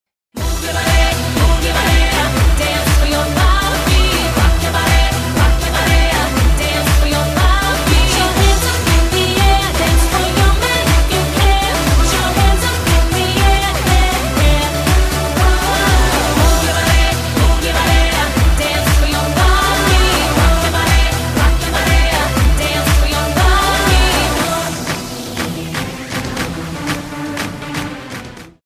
• Качество: 128, Stereo
поп
громкие
женский вокал
зажигательные
dancehall
Latin Pop